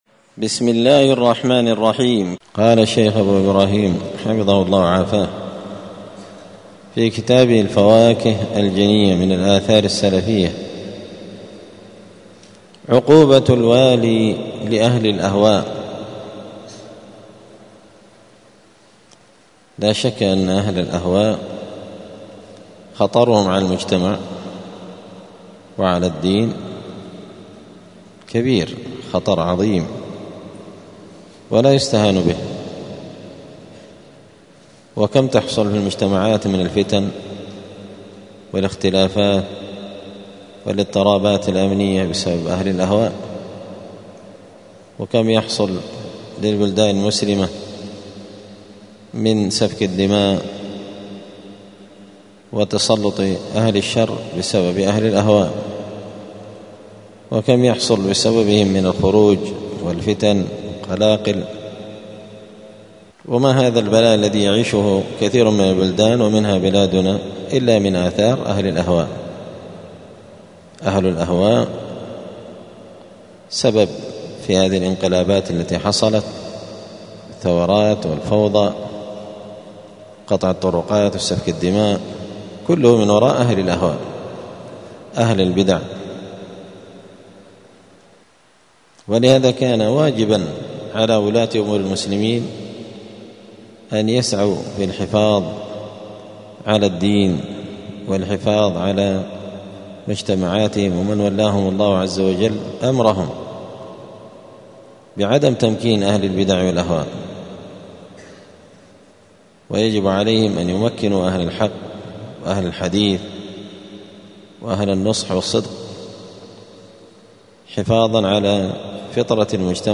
دار الحديث السلفية بمسجد الفرقان بقشن المهرة اليمن
*الدرس الحادي والسبعون (71) {عقوبة الوالي لأهل الأهواء}*